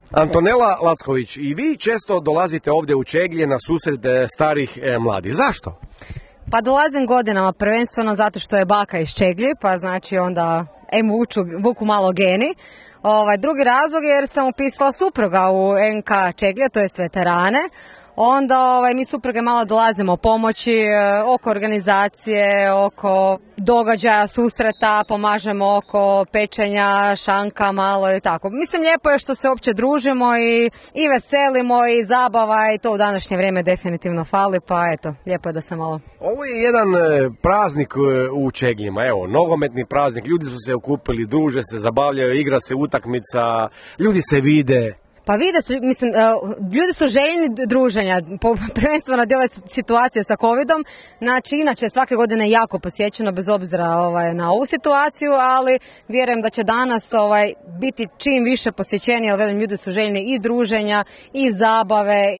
Bili smo u Čegljima na susretu starih i mladih